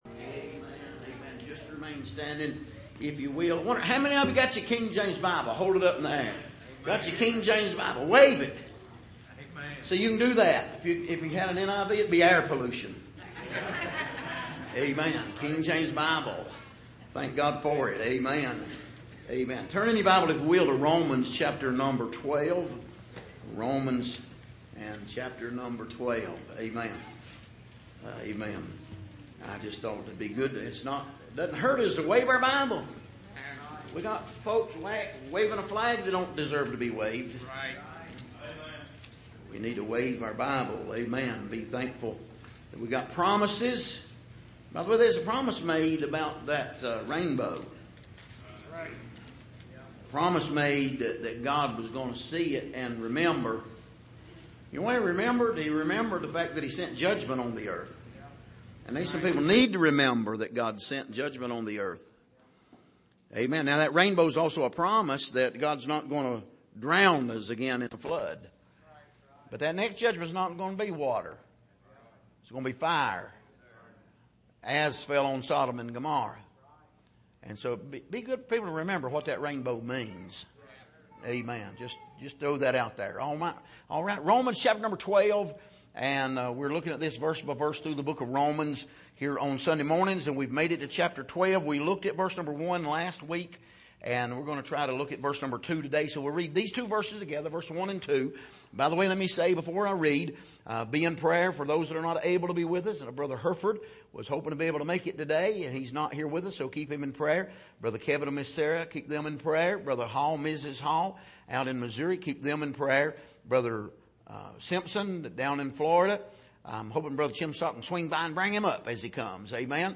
Passage: Romans 12:1--2 Service: Sunday Morning